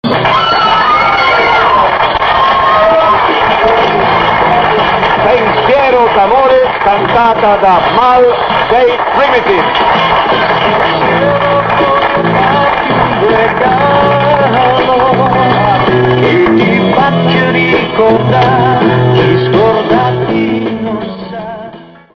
I brani musicali sono sfumati per le solite esigenze di tutela del copyright.